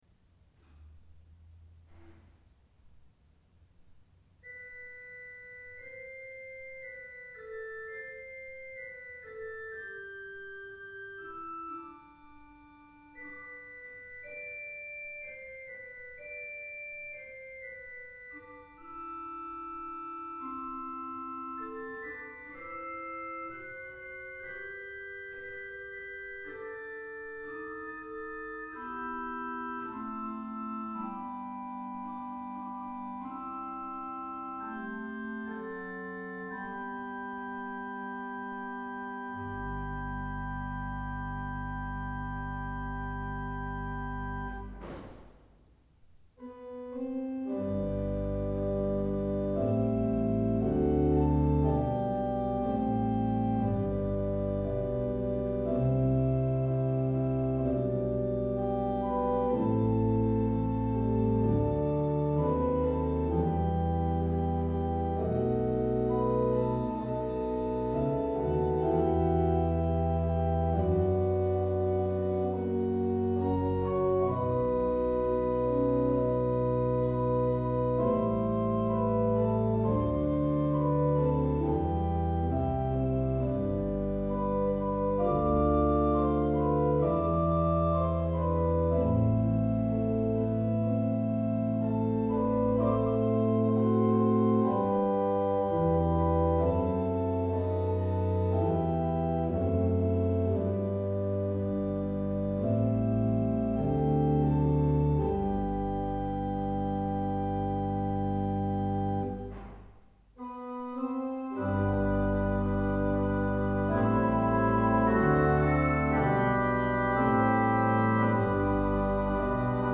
Det er makt i de foldede hender Spilt inn i Sandar Kirke, noen småfeil.
Flentrop Orgel
Sandar Kirke   ZOOM H4n 05.08.2012